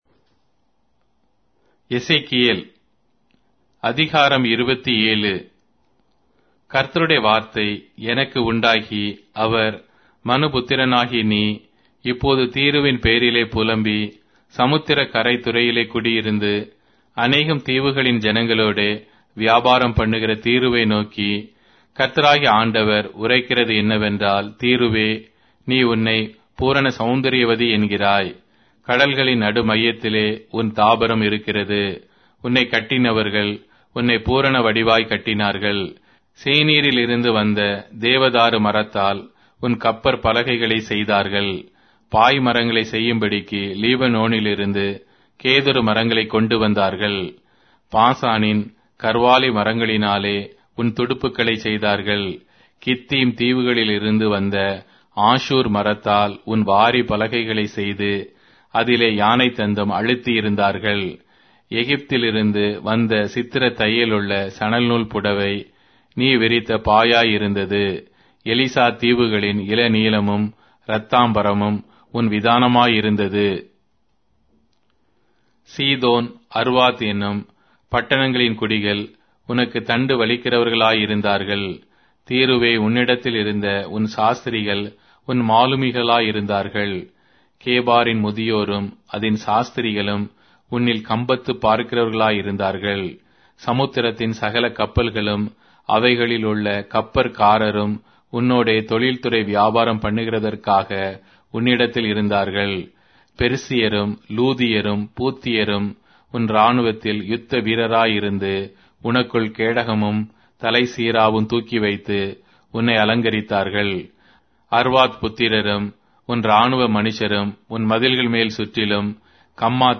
Tamil Audio Bible - Ezekiel 22 in Tov bible version